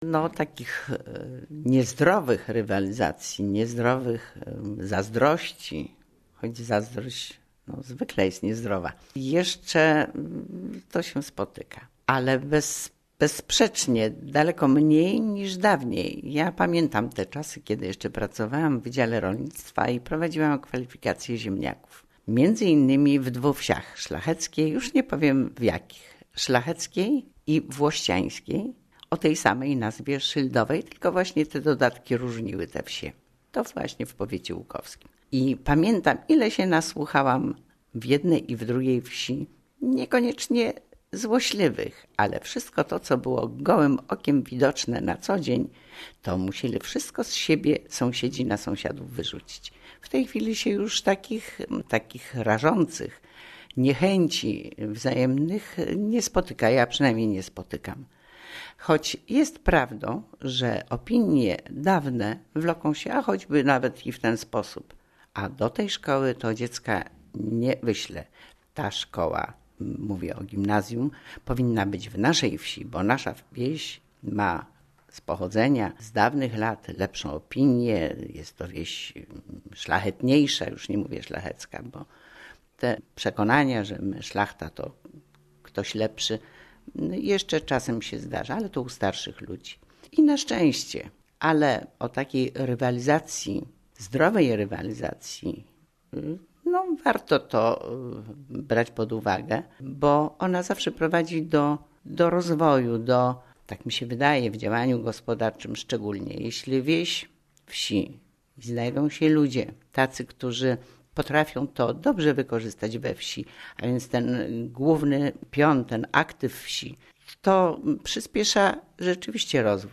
Wójt Gminy Łuków